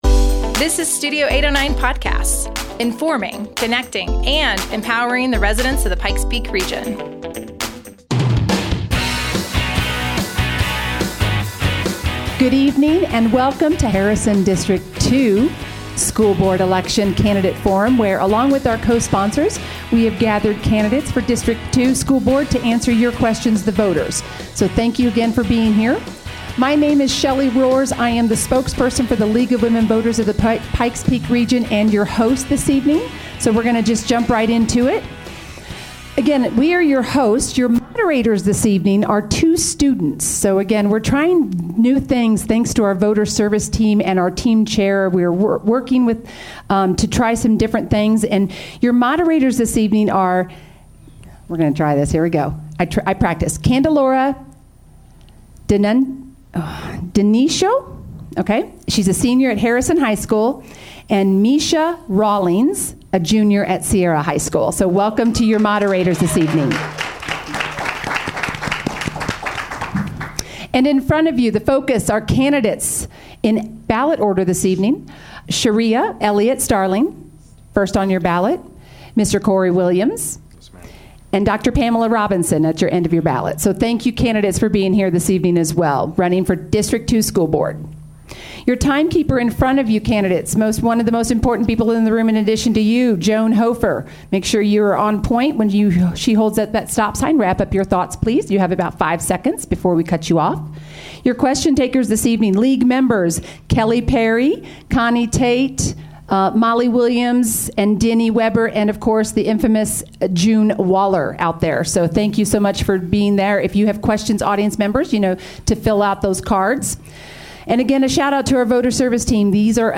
Harrison District 2 Candidate Forum 2023
Meet the candidates in this forum hosted by the League of Women Voters of the Pikes Peak Region, with community partners.